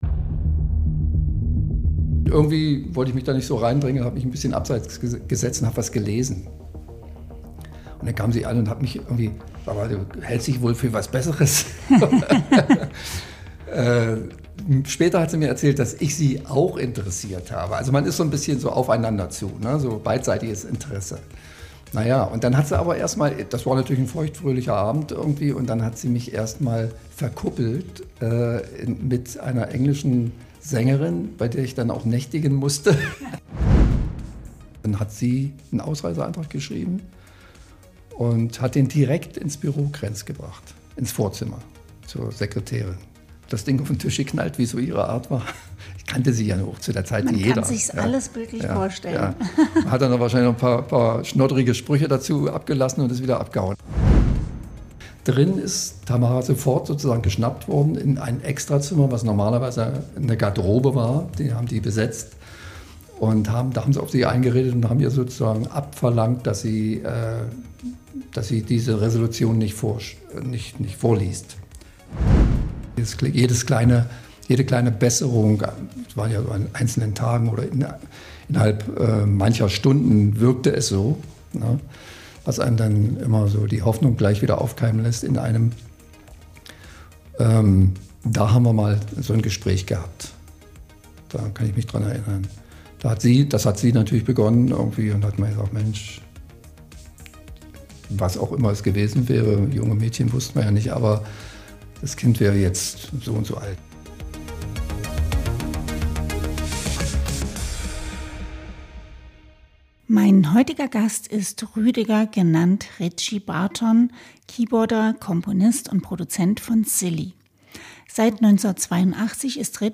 Silly-Keyboarder, Komponist und Produzent Ritchie Barton im Gespräch